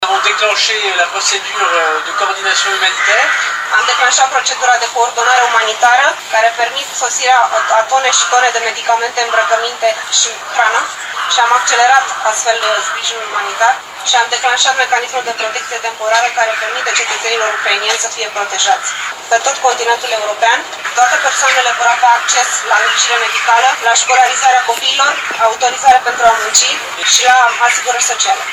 Astăzi s-a aflat, în Vama Siret, ministrul de Interne francez GERALD DARMANIN, care a folosit prilejul pentru a saluta implicarea autorităților și voluntarilor români în gestionarea crizei refugiaților.